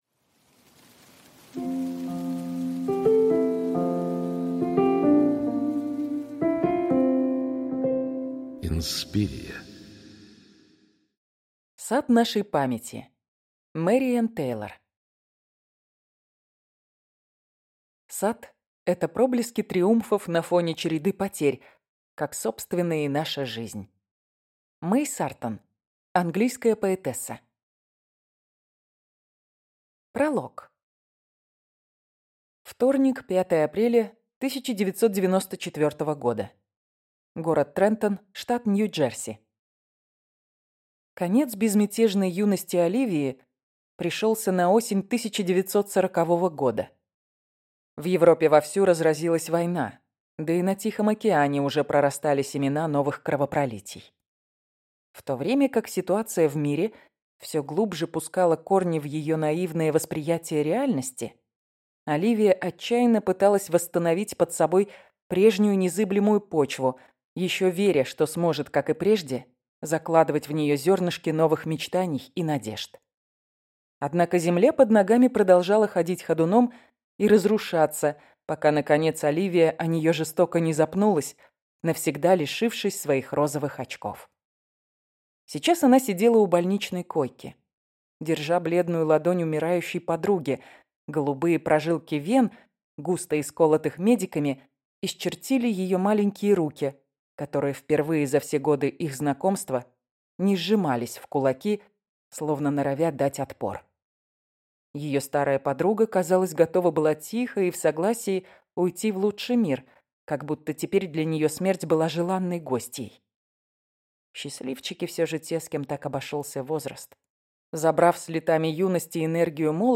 Аудиокнига Сад нашей памяти | Библиотека аудиокниг